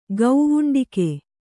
♪ gauvuṇḍike